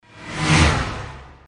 OvertakingSound_4.mp3